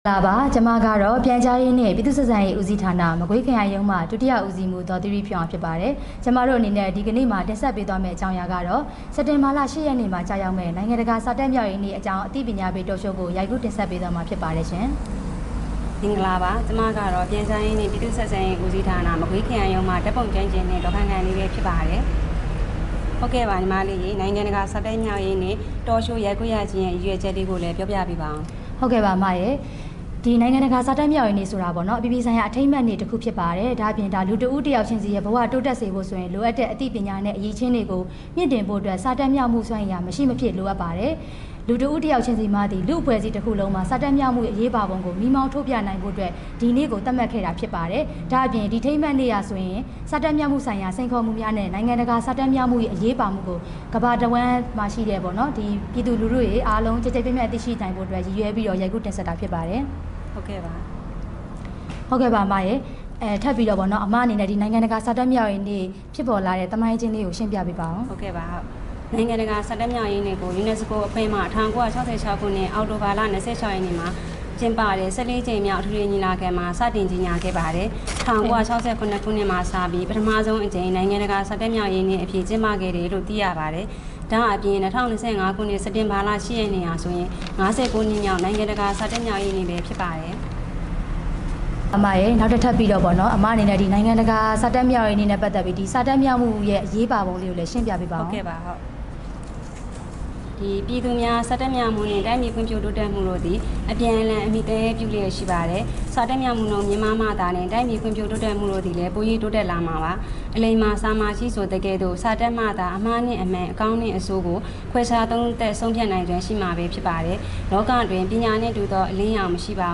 နိုင်ငံတကာစာတတ်မြောက်ရေးနေ့အကြောင်းအသိပညာပေး Talk Show